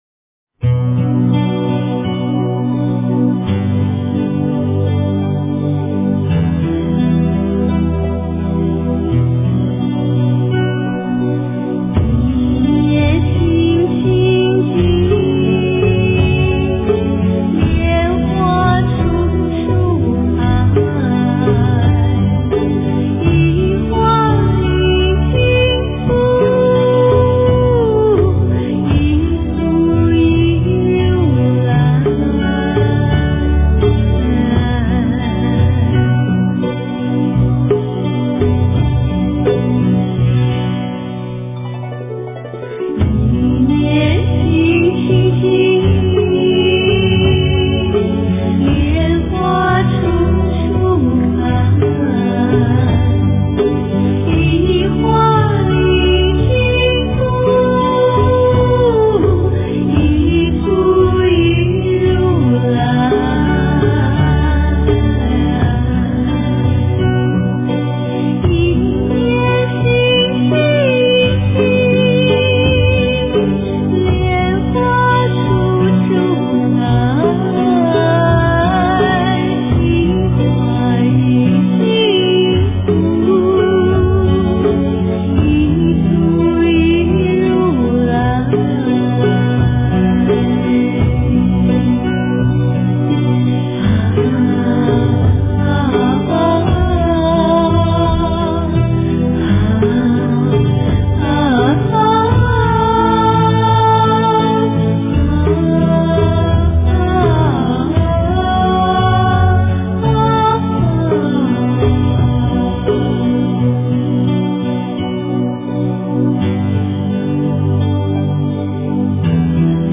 佛音 诵经 佛教音乐 返回列表 上一篇： 大悲咒-车载版 下一篇： 心经 相关文章 忏悔文--佚名 忏悔文--佚名...